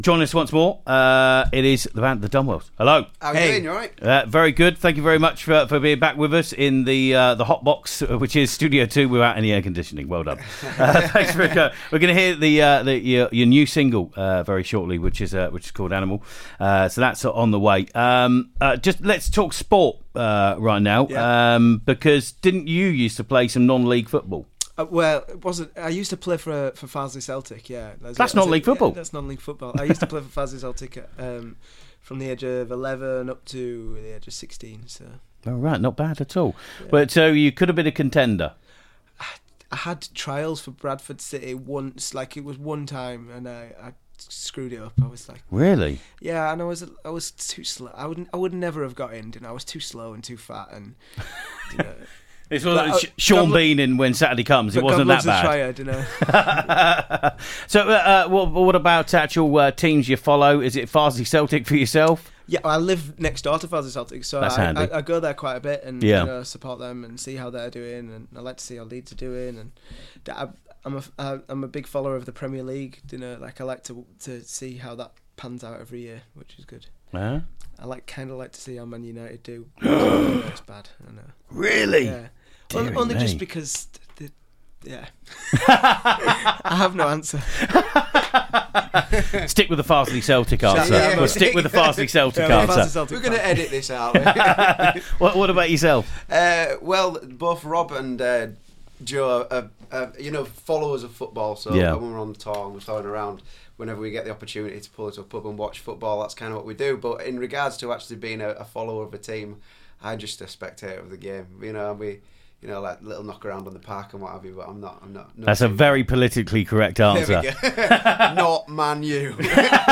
Amazing session